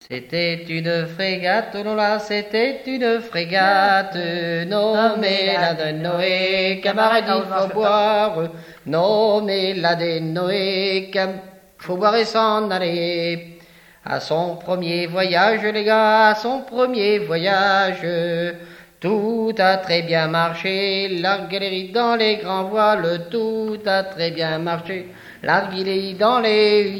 Mission Ile-d'Yeu
chansons traditionnelles
Pièce musicale inédite